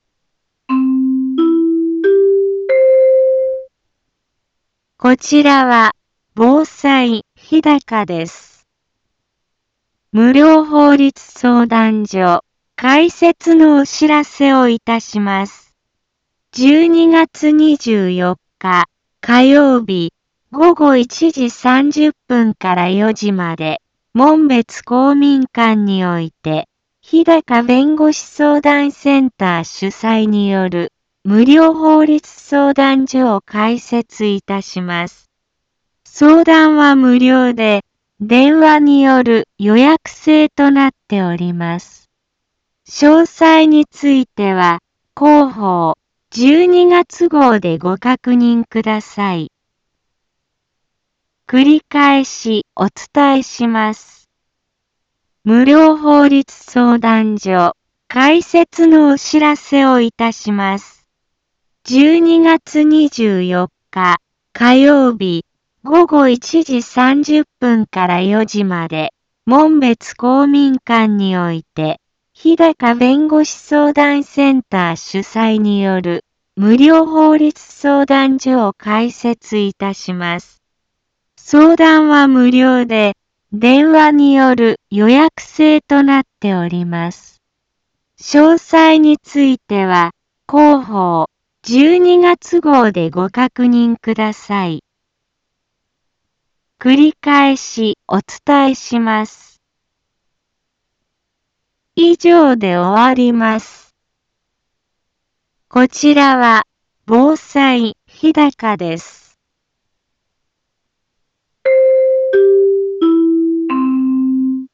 一般放送情報
Back Home 一般放送情報 音声放送 再生 一般放送情報 登録日時：2019-12-16 10:04:03 タイトル：無料法律相談所開設のお知らせ インフォメーション：こちらは、防災日高です。